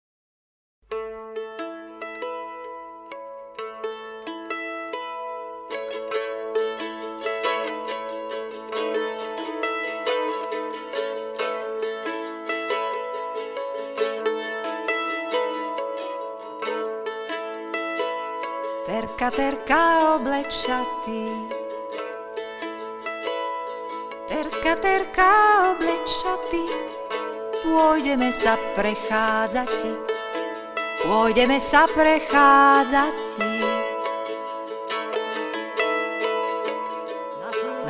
zjevem i zpěvem slovenská lidová madona